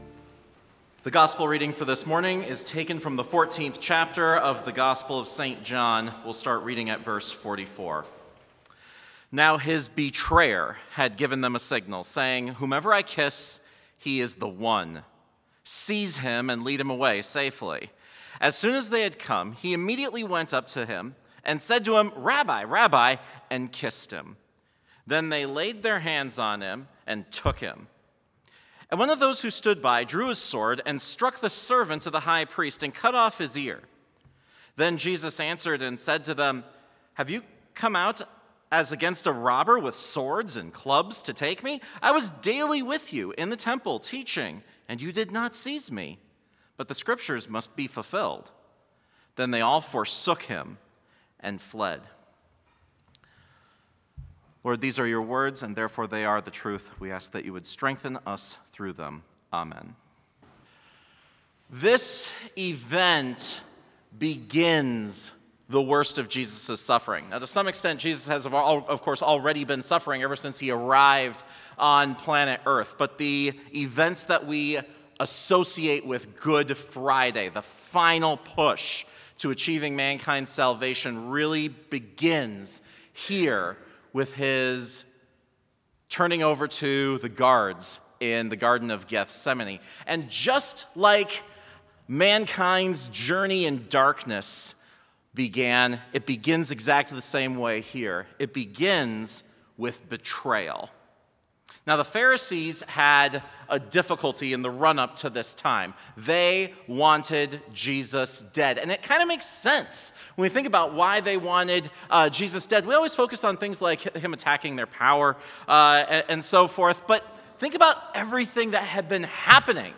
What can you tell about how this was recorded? Complete service audio for Chapel - March 22, 2022